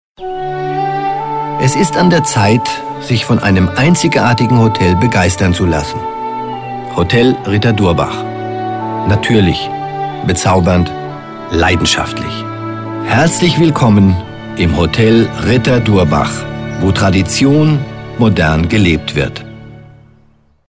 Marcel Reif für Hotel Ritter – Online Spot